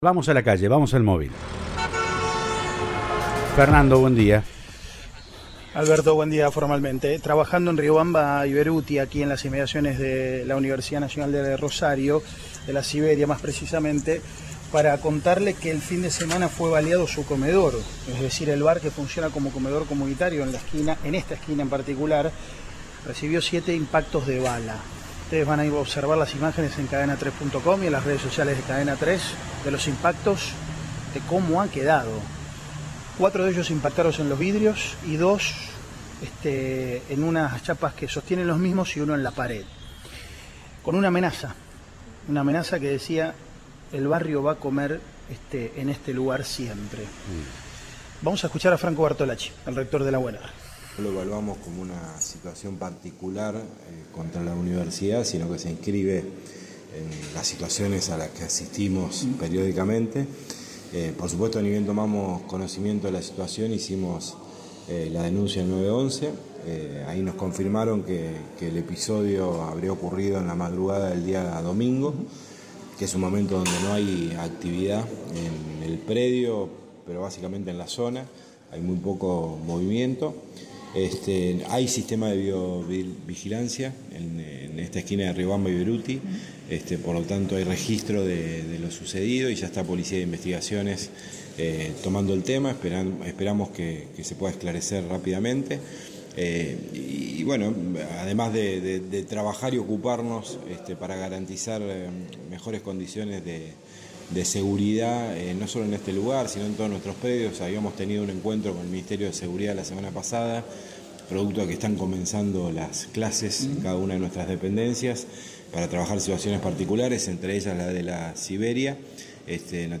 Franco Bartolacci, rector de la Universidad Nacional de Rosario, dijo al móvil de Cadena 3 Rosario que no entienden el ataque contra el comedor como algo directo hacia la institución.